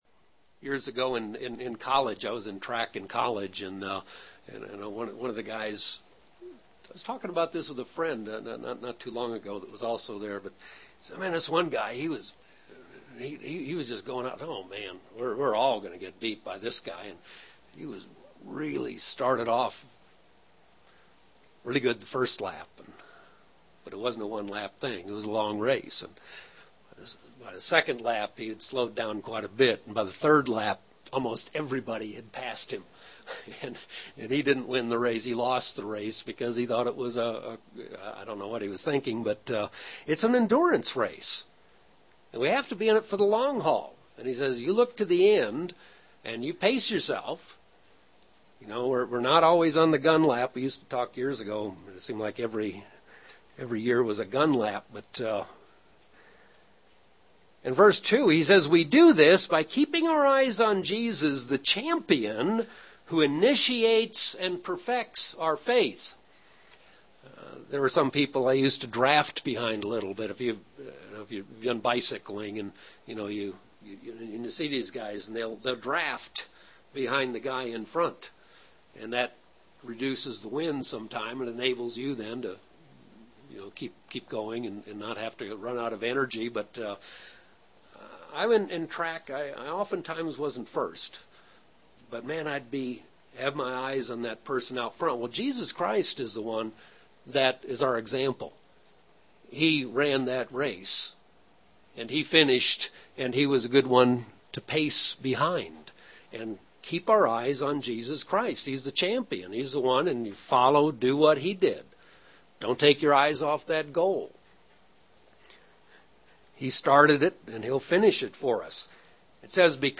Paul, in several of his writings, compares our spiritual journey into the Kingdom to various sporting events. This sermon explores some of those analogies and admonishes us to fight the good fight so that we might receive the crown of righteousness in the Kingdom.